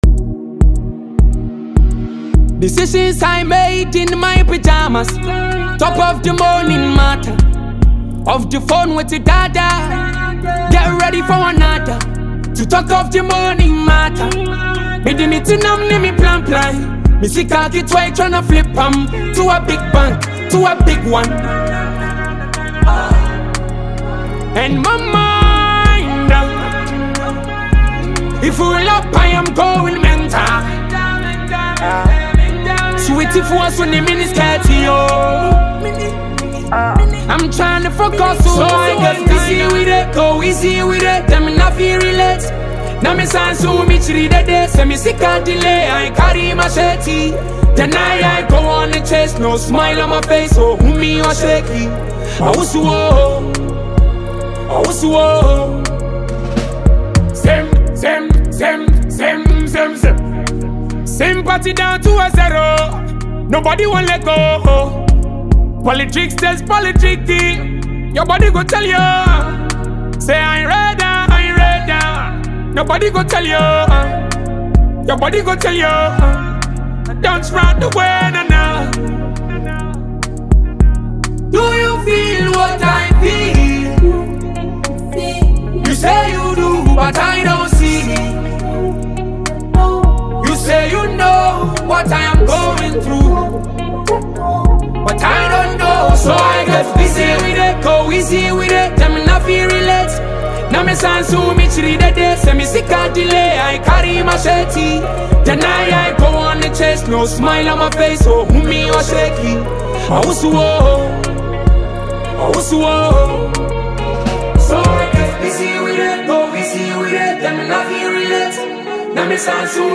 An energetic, long-awaited hit composition
outdated by a Ghanaian songwriter and vocalist